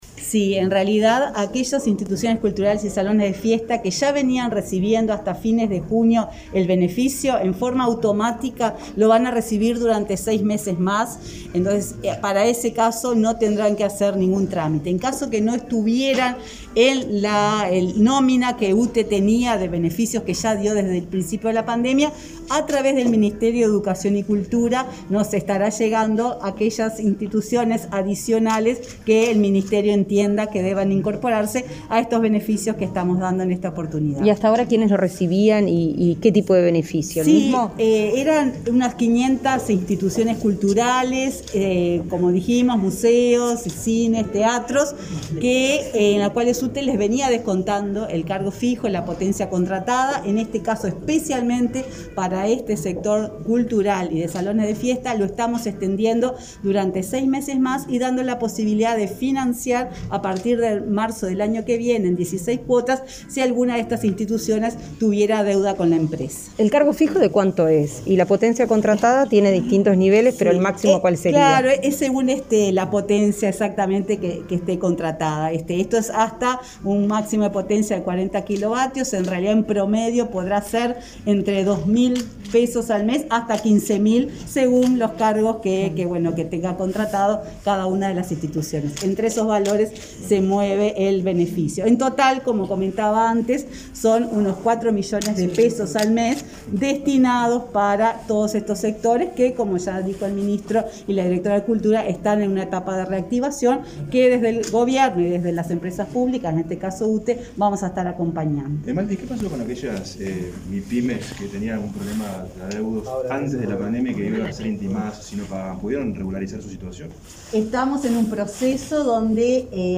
Declaraciones a la prensa de la presidenta de UTE, Silvia Emaldi
Declaraciones a la prensa de la presidenta de UTE, Silvia Emaldi 10/09/2021 Compartir Facebook X Copiar enlace WhatsApp LinkedIn Este 10 de setiembre, UTE y el Ministerio de Educación y Cultura anunciaron una medida de apoyo a instituciones culturales y salones de fiestas, de la exoneración de cargos fijos y por potencia contratada durante el segundo semestre de 2021. Tras comunicar la noticia, la titular del ente brindó declaraciones a la prensa.